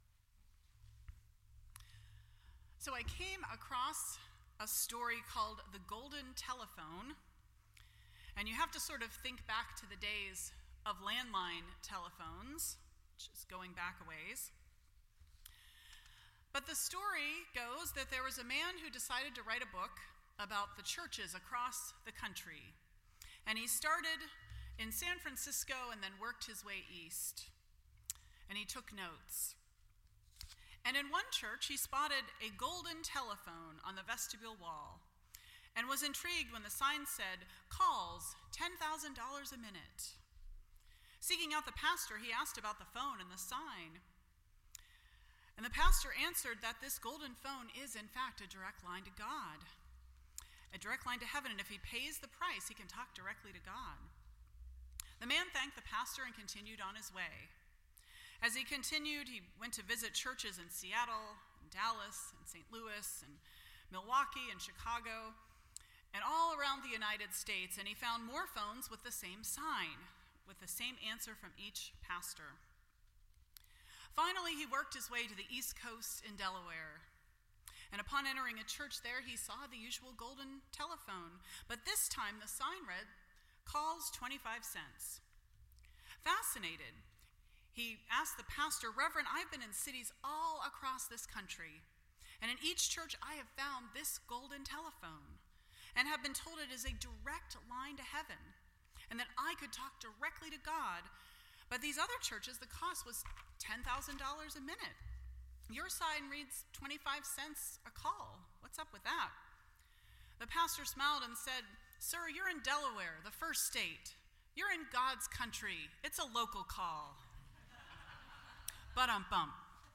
Series: The Lord's Prayer Service Type: Sunday Morning %todo_render% Share This Story